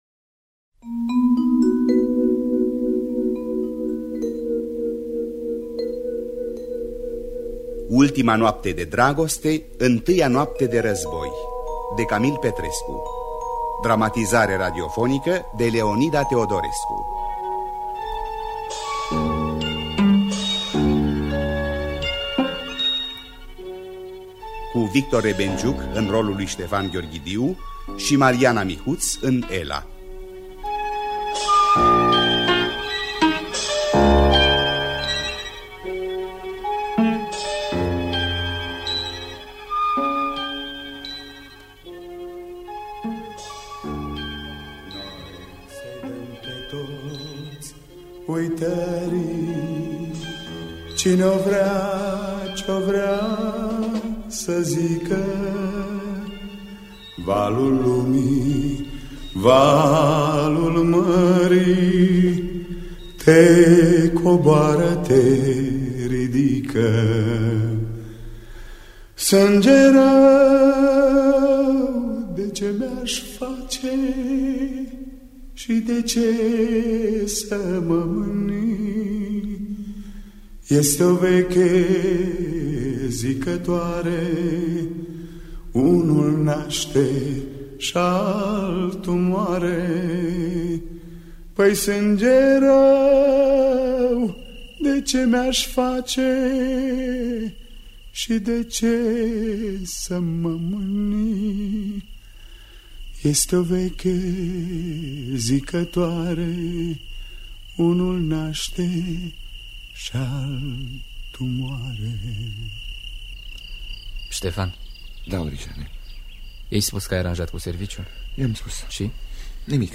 Voci din piesa radiofonică din anul 1973